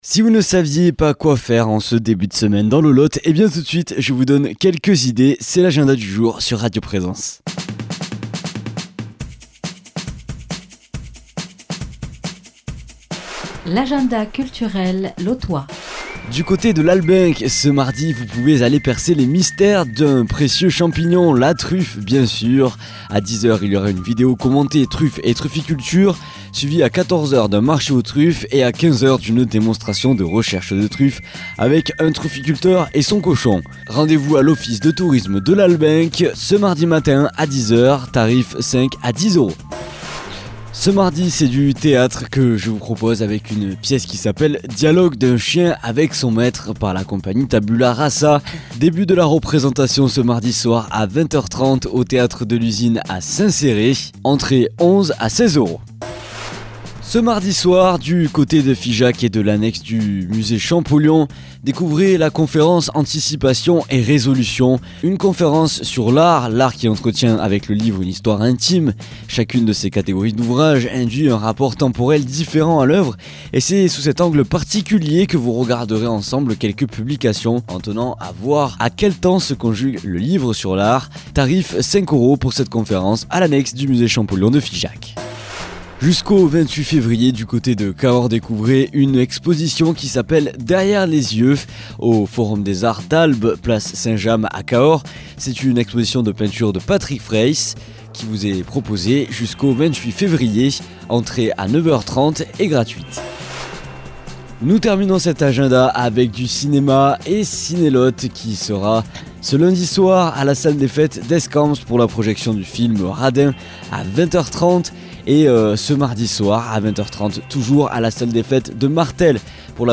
Blues
Présentateur